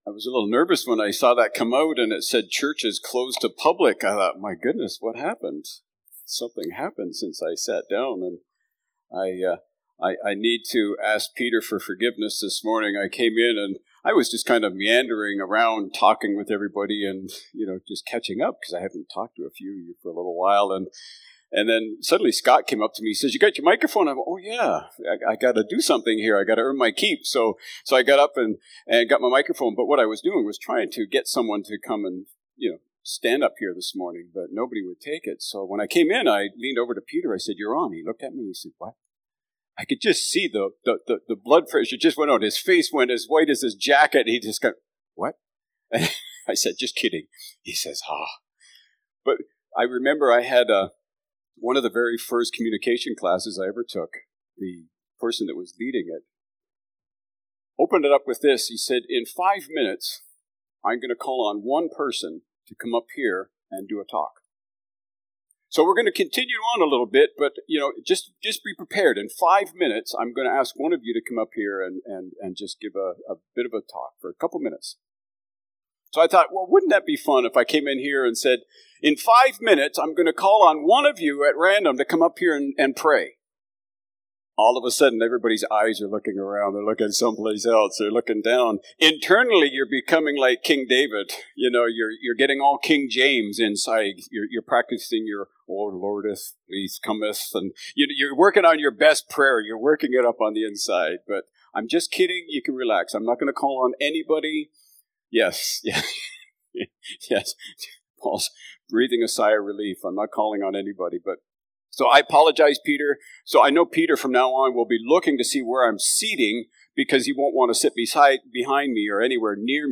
August-3-Sermon.mp3